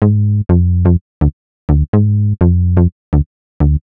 cch_synth_loop_carbrini_125_Fm.wav